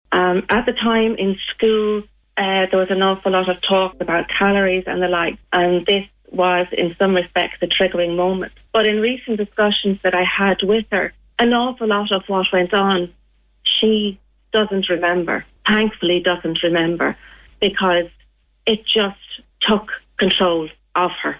Speaking on Kildare Today, local woman